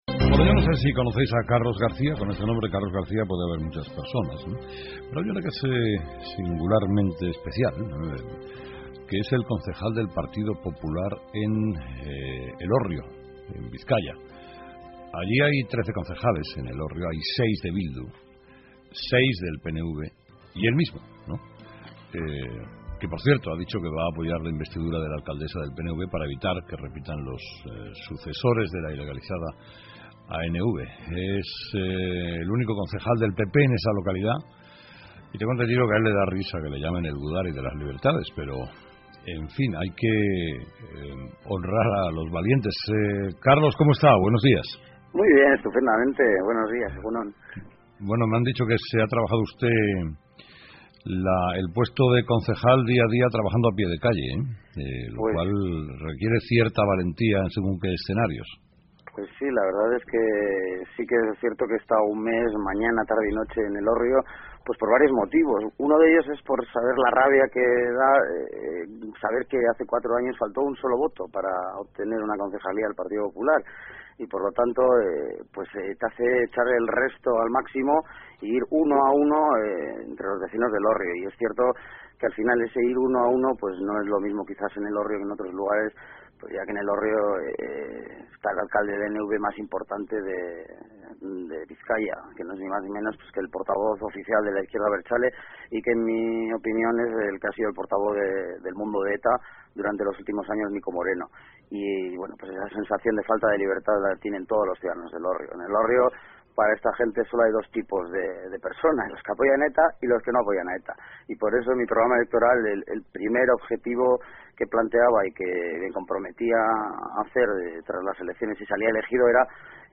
Entrevista con Carlos García -Elorrio